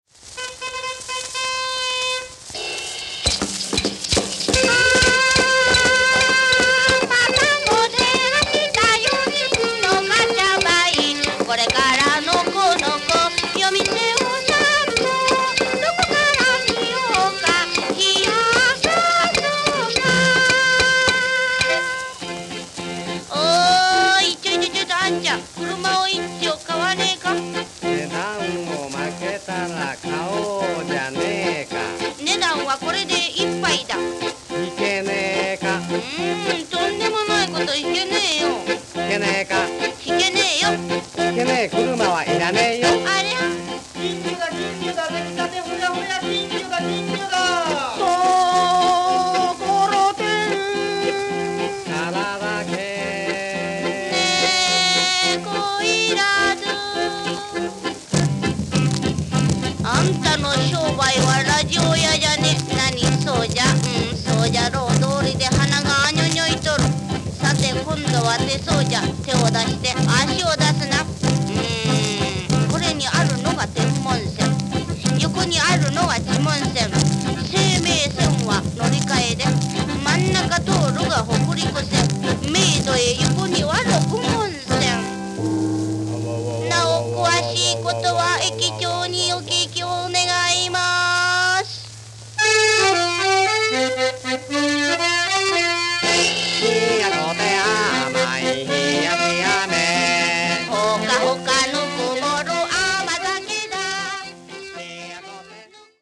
長唄や浪花節、民謡、浄瑠璃などの邦楽に、ジャズやハワイアンを巧みに取り入れた洒脱な音楽性が本当に見事で◎！
プレスリリースには“阿呆陀羅経を強烈にスィングさせたかれら一流の和製ジャイヴ”とありましたが、リズム感も抜群ですね！